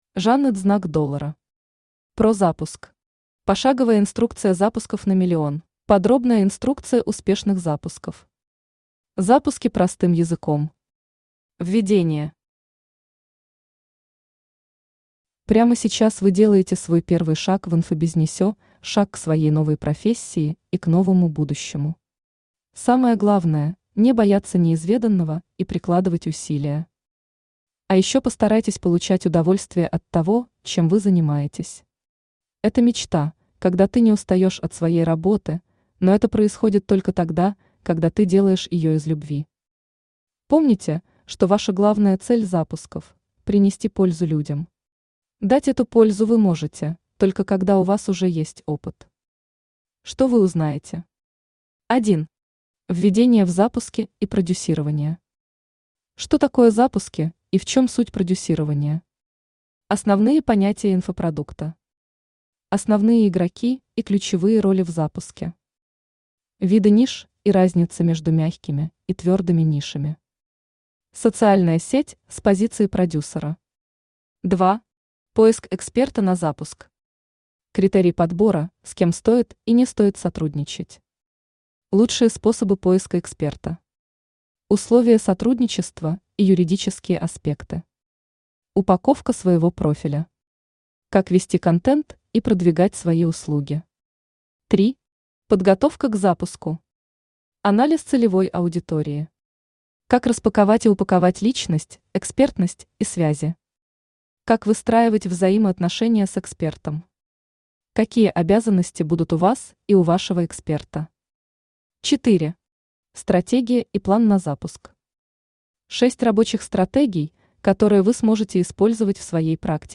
Пошаговая инструкция запусков на миллион Автор Zhannet $ Читает аудиокнигу Авточтец ЛитРес.